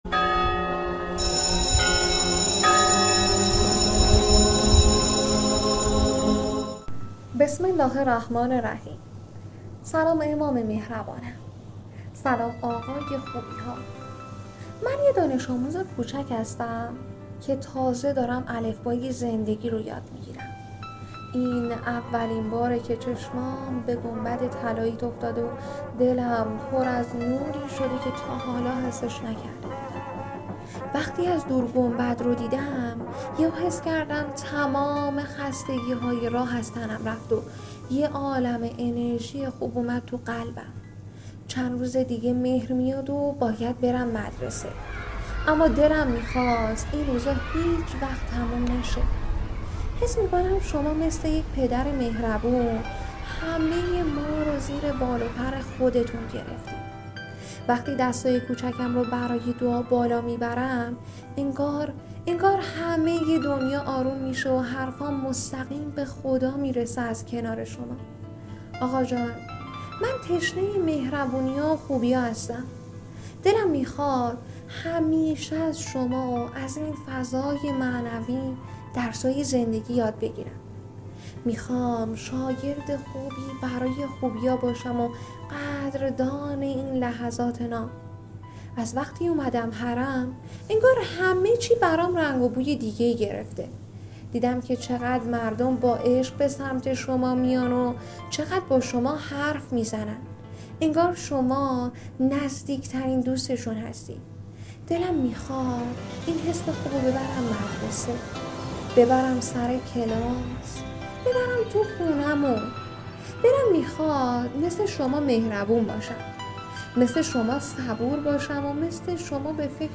شاگرد اولیِ حرم نامه‌ای ساده و صمیمی از زبان یک دانش‌آموز است. گزارشی از نخستین دیدارِ کودکی مشتاق با گنبد طلایی و فضای معنوی حرم. این روایت کوتاه، با زبانی کودکانه و پاک، حس آرامش، امید و شوق به یادِ امام مهربان را به شنونده منتقل می‌کند.